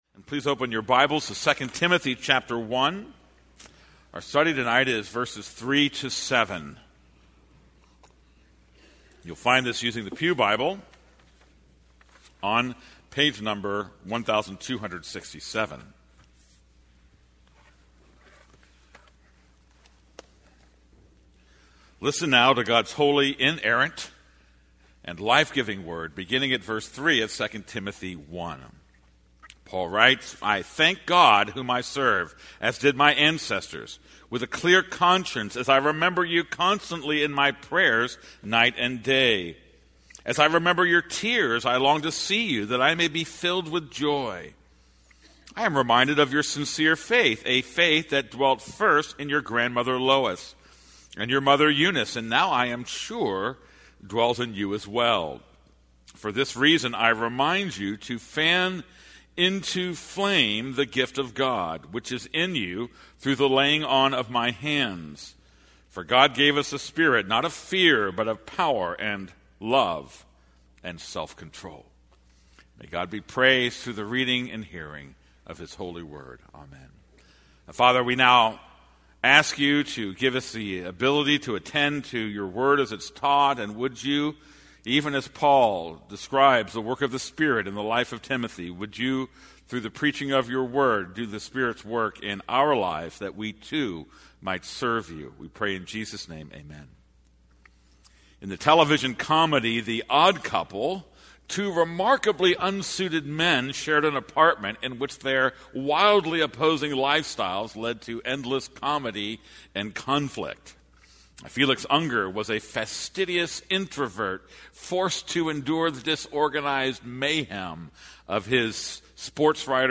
This is a sermon on 2 Timothy 1:3-7.